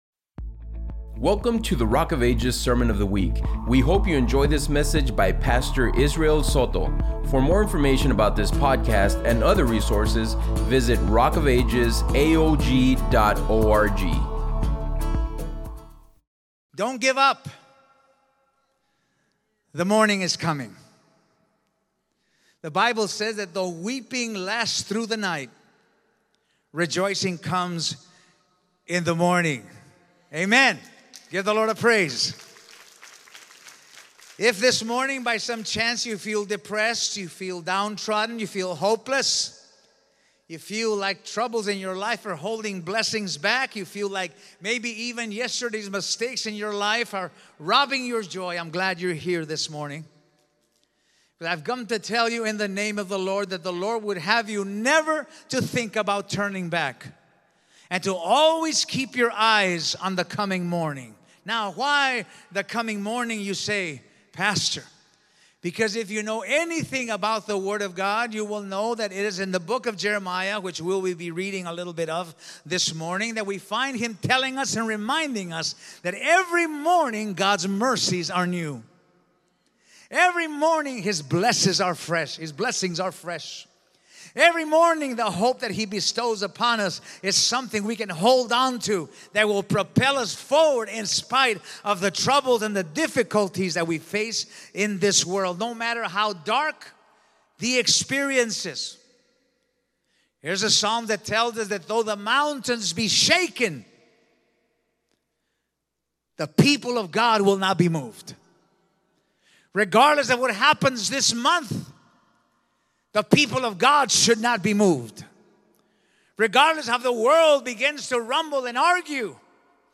SERMONS - Rock of Ages AOG